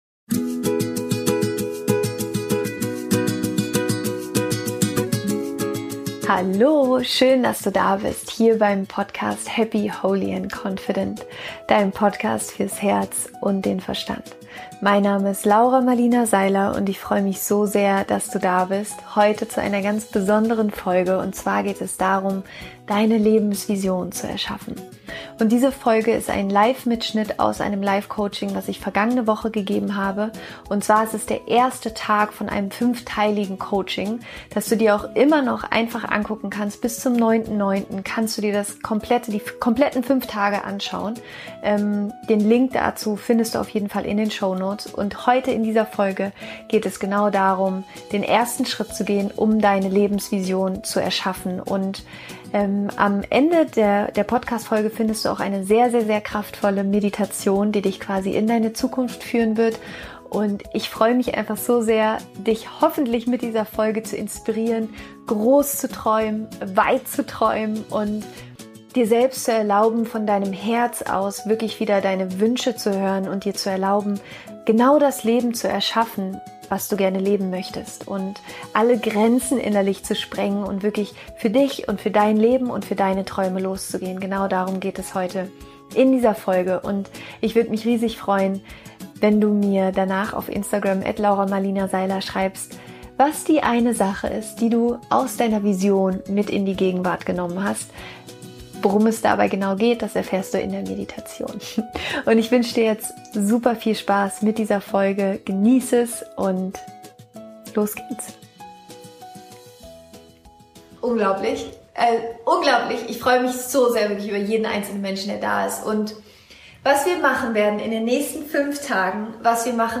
Diese Folge ist ein Mitschnitt von einem Live Coaching, das ich letzte Woche gegeben habe.
Es geht darum, was der erste und wichtigste Schritt ist, wenn du dir deine Lebensvision erschaffen möchtest. Am Ende der Folge gibt es außerdem eine kraftvolle Meditation, die dich in deine Zukunft führen wird.